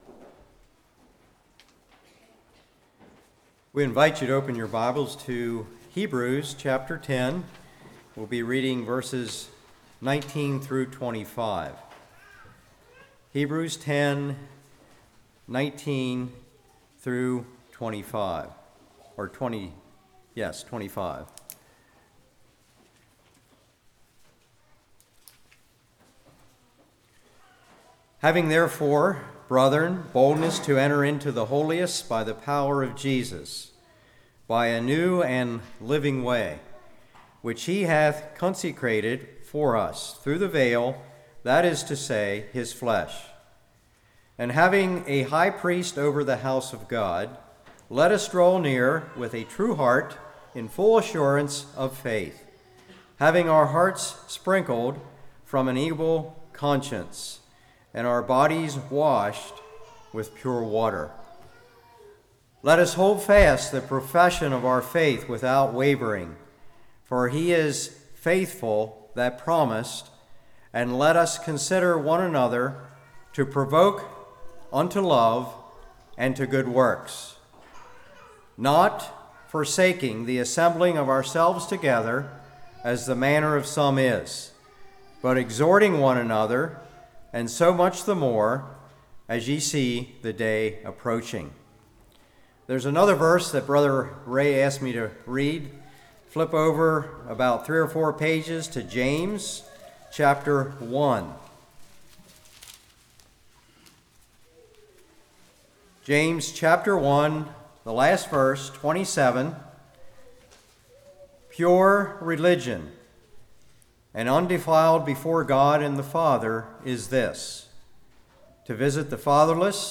Hebrews 10:19-25 Service Type: Morning Fellowship Is Not An Option How Is Your Interaction With The Body?